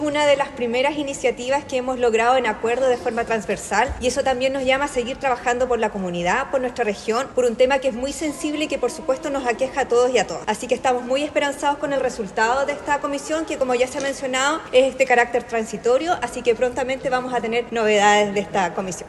La consejera Marión Fernández señaló que este proyecto marca un hito en la zona y es el primer acuerdo logrado de forma transversal.